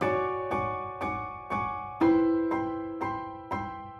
Index of /musicradar/gangster-sting-samples/120bpm Loops
GS_Piano_120-D1.wav